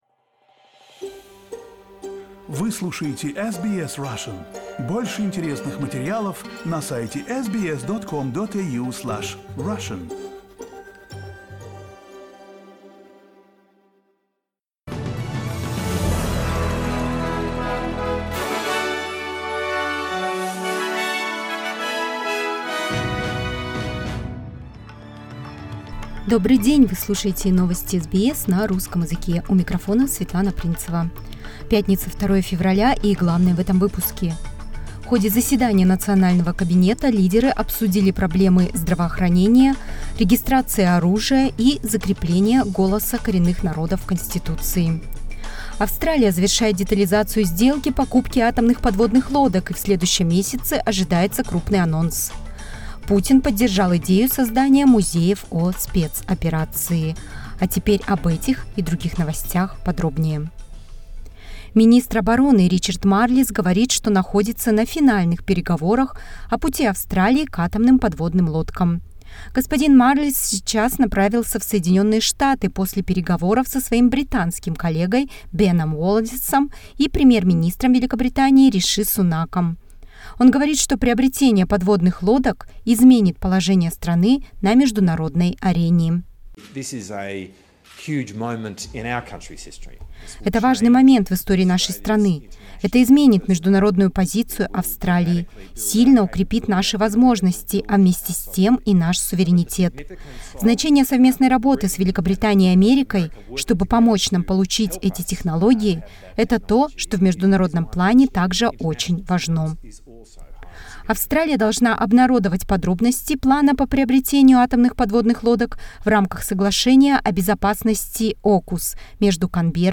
SBS news in Russian — 03.02.2023